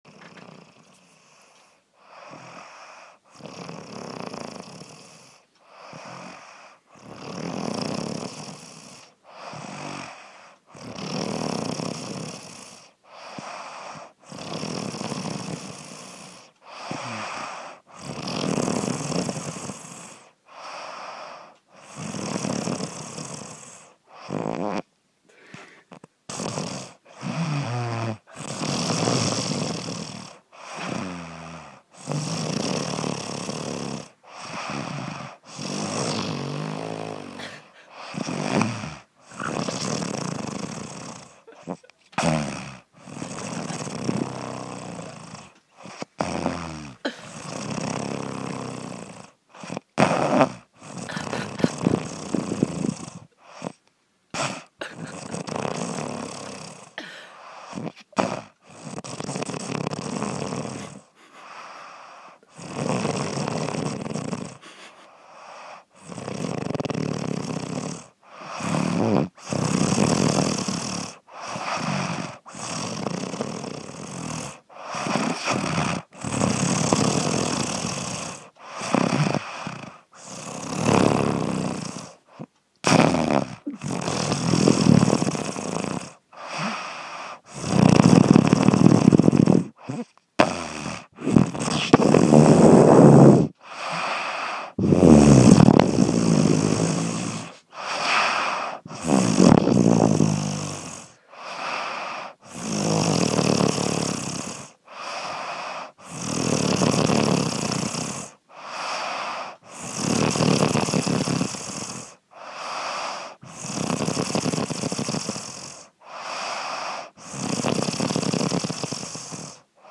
Звуки дыхания, храпа
Долгий храп у человека